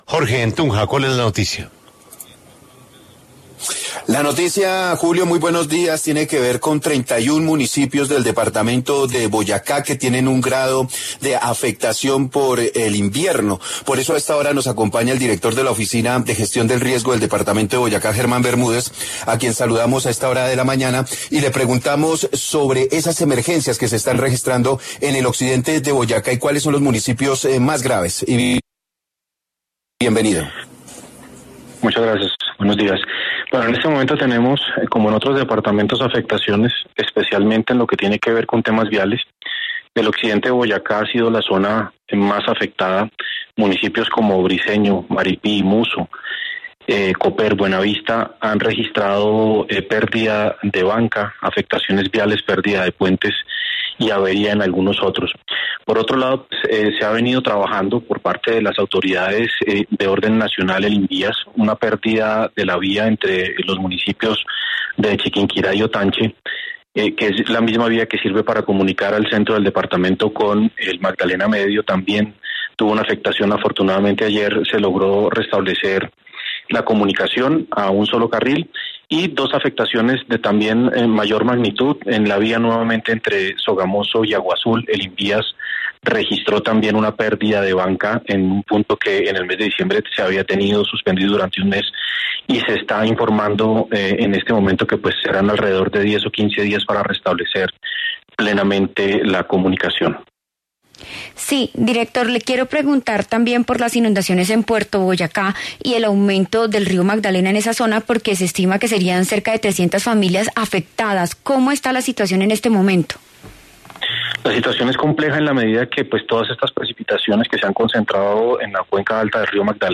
En diálogo con La W, el director de la Oficina para la Gestión del Riesgo de Boyacá, Germán Bermúdez, sostuvo que 31 municipios del departamento tienen algún grado de afectación.